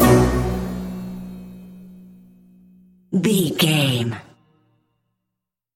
Uplifting
Aeolian/Minor
F#
percussion
flutes
piano
orchestra
double bass
silly
circus
goofy
comical
cheerful
perky
Light hearted
quirky